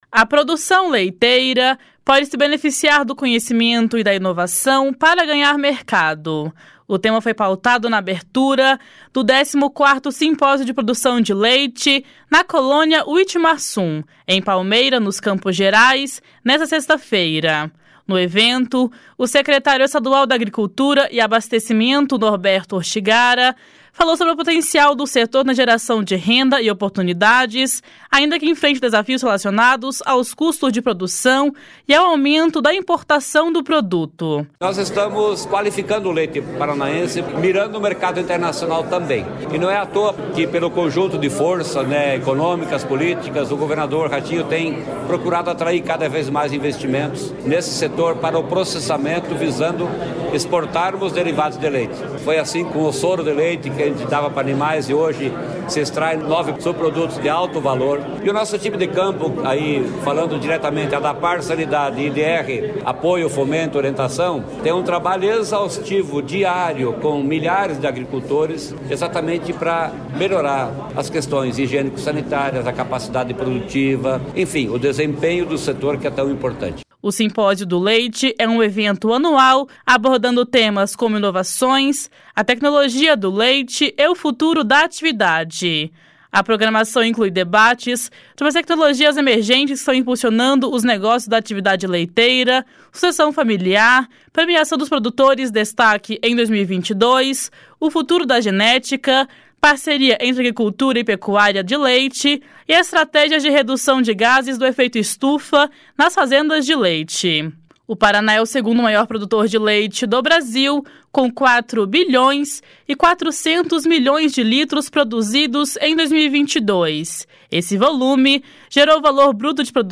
O tema foi pautado na abertura do 14º Simpósio de Produção de Leite na Colônia Witmarsum, em Palmeira, nos Campos Gerais, nesta sexta-feira. No evento, o secretário estadual da Agricultura e Abastecimento, Norberto Ortigara, falou sobre o potencial do setor na geração de renda e oportunidades, ainda que enfrente desafios relacionados aos custos de produção e ao aumento da importação do produto. // SONORA NORBERTO ORTIGARA //